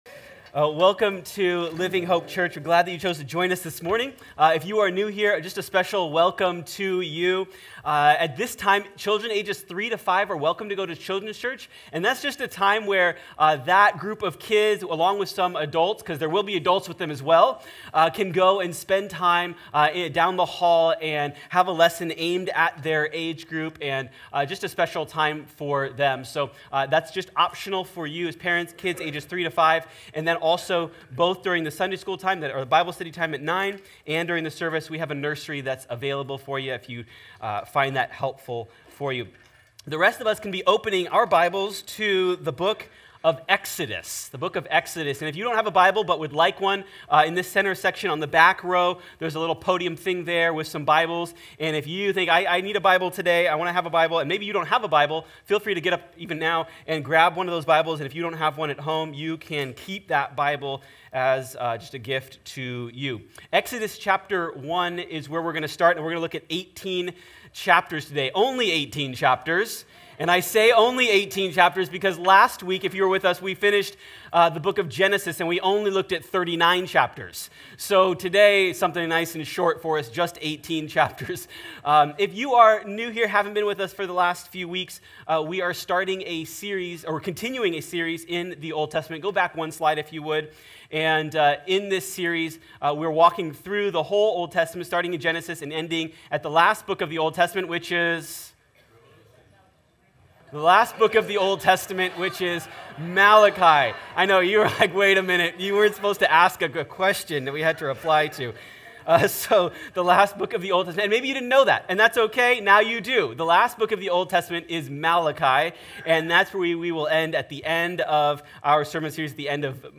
Join us as our Elders teach and we read through the entire Old Testament in one year!